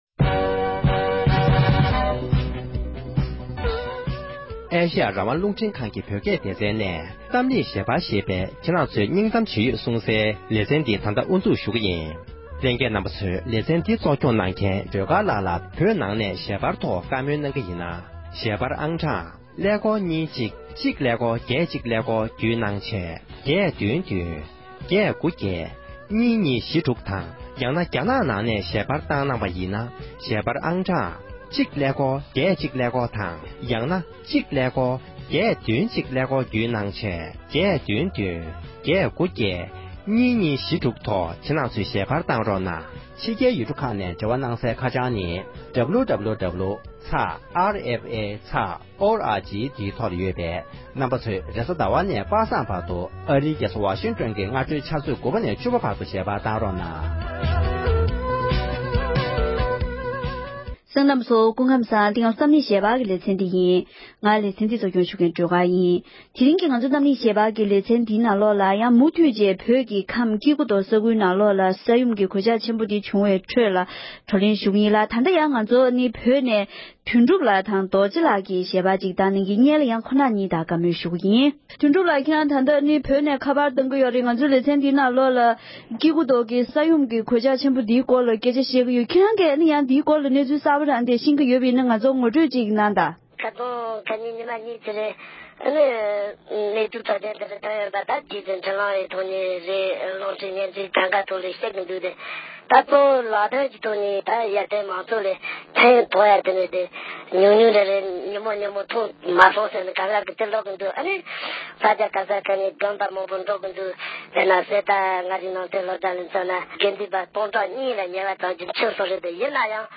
བོད་ཕྱི་ནང་གཉིས་སུ་ཡོད་པའི་བོད་མི་ཁག་ཅིག་གི་ལྷན་གླེང་མོལ།